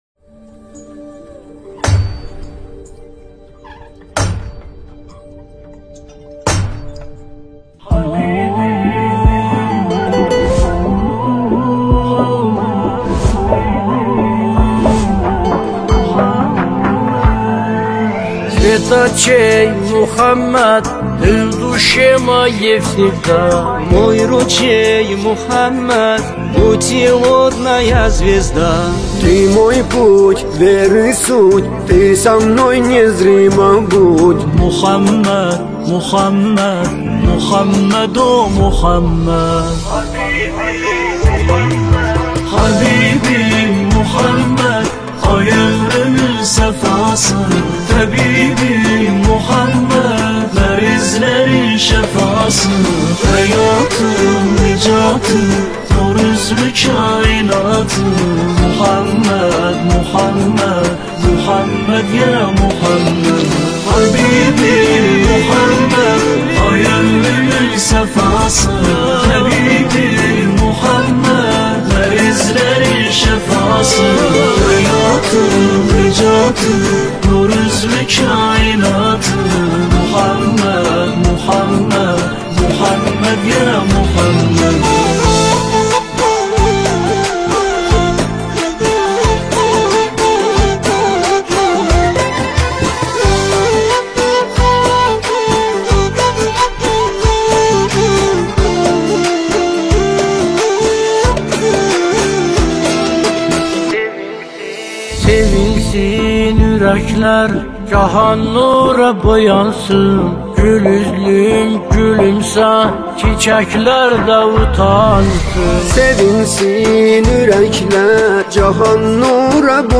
نماهنگ زیبای آذری_روسی